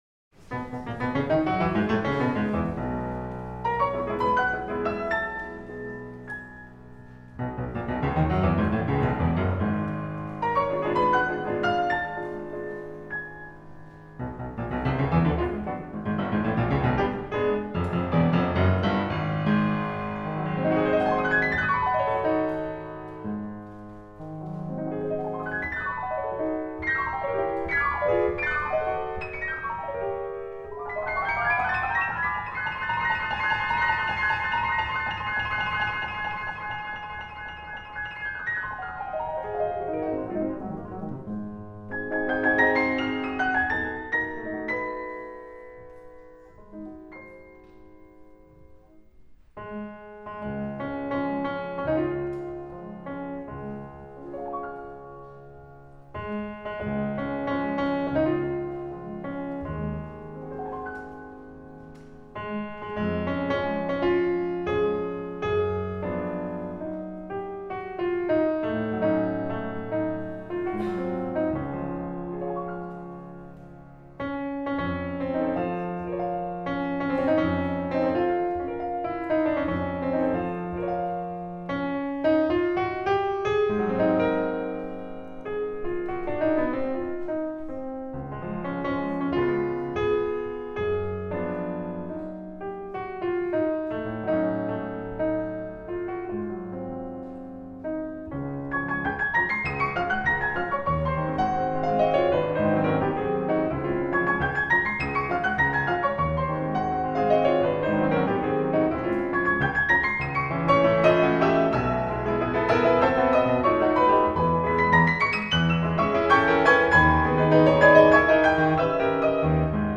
LIVE- Mitschnitt aus dem Wiener Musikverein
BELCANTO PIANISTICO IM MUSIKVEREIN